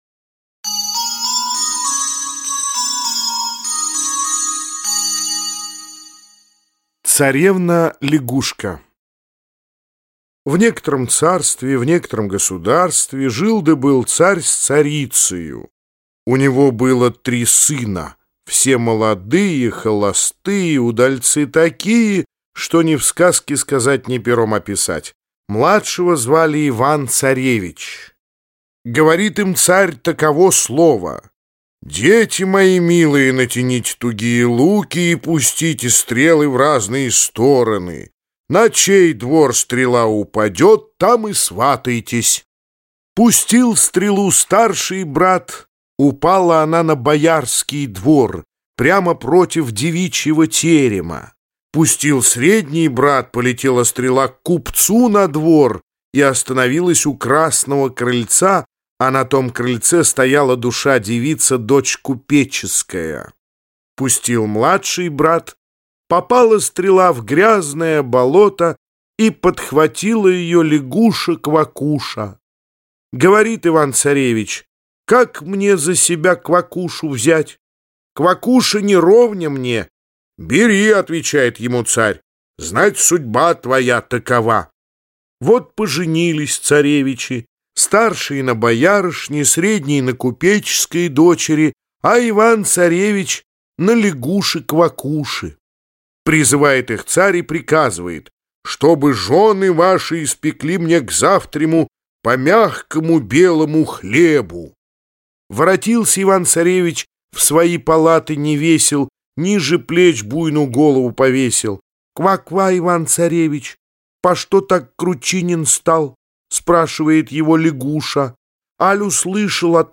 Аудиокнига Большая книга о Бабе-Яге | Библиотека аудиокниг